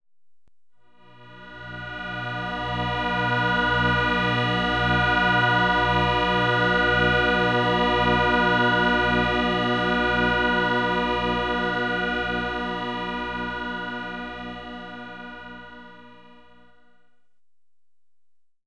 H mit 485 Hz und 432 Hz Kammerton,
mit Schwebung im Theta Bereich,
inklusive tieferen H Oktaven